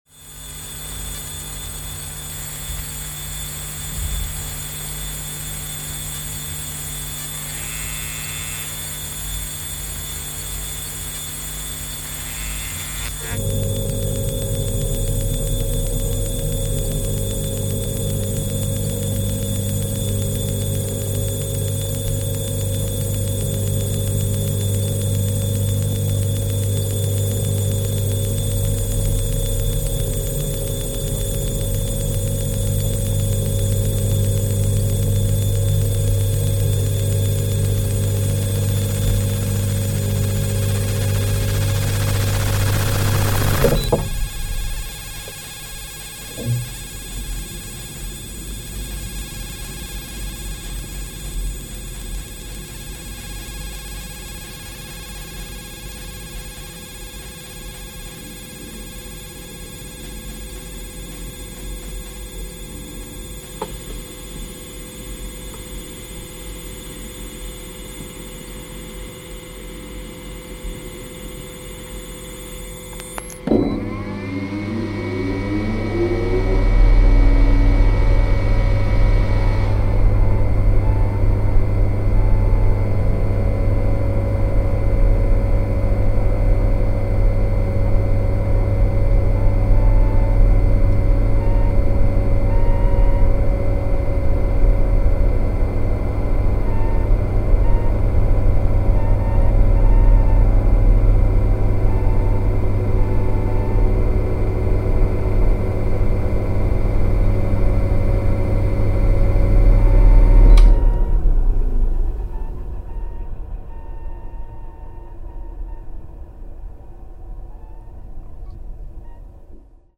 From the overt to the inaudible, I experiment with its dimensions, repel its indeterminacy.